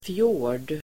Ladda ner uttalet
Uttal: [fjå:r_d el. fjo:r_d]